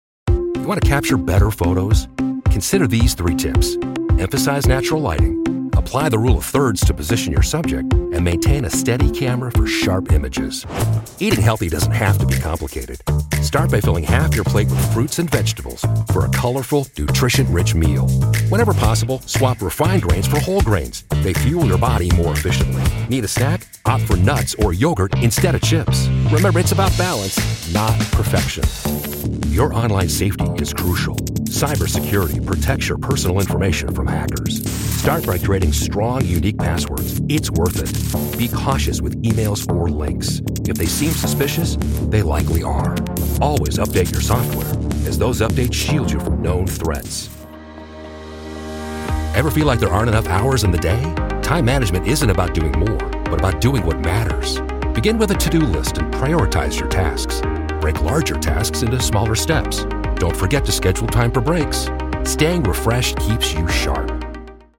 Male
Adult (30-50), Older Sound (50+)
I deliver a warm, friendly, relatable tone with clarity and articulation. I also possess a versatile and dynamic range that is energetic and enthusiastic, as well as authoritative and confident.
E-Learning